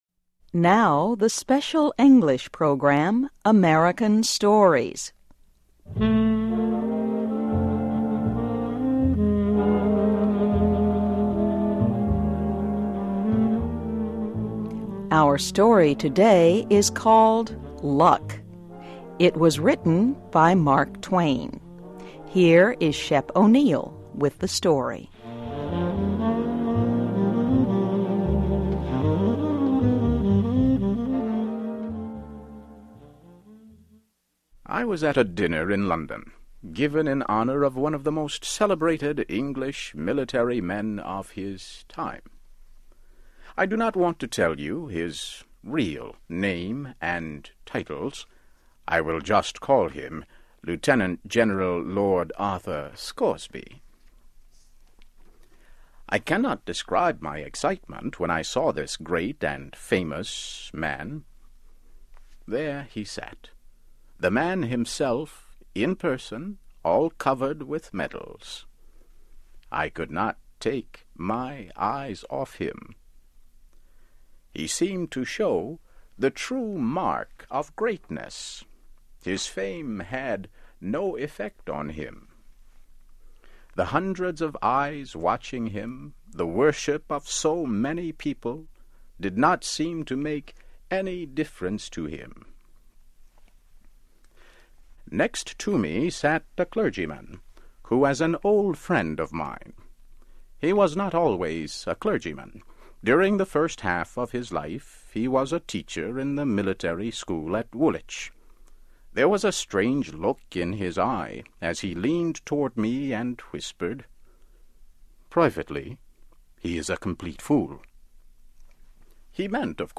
(MUSIC) Our story today is called "Luck."